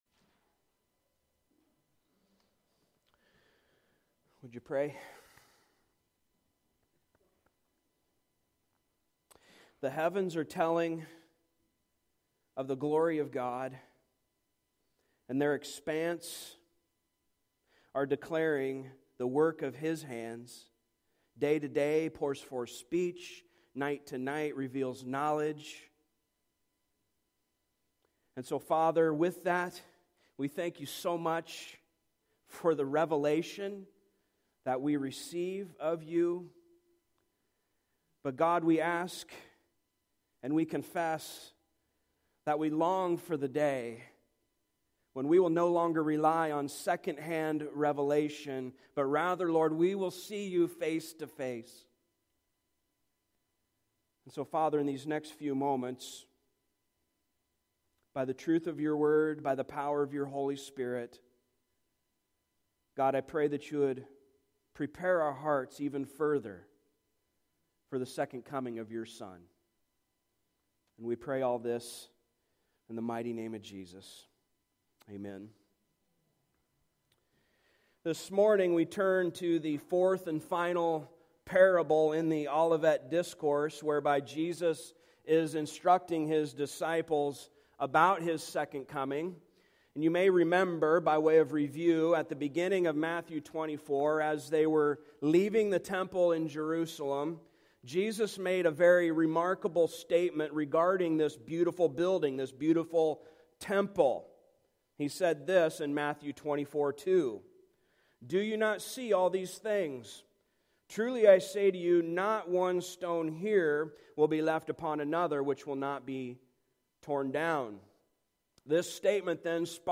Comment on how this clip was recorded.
Passage: Matthew 25:31-46 Service Type: Sunday Morning